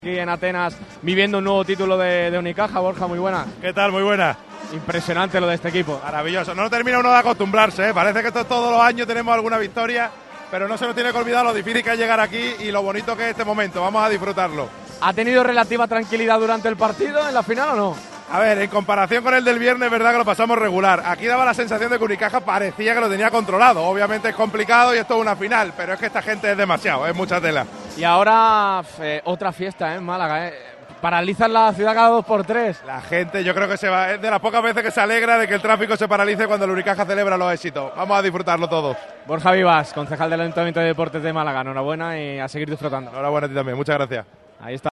Las reacciones de los campeones de la BCL sobre el parqué del Sunel Arena.
BORJA VIVAS, CONCEJAL DE DEPORTES DEL AYUNTAMIENTO DE MÁLAGA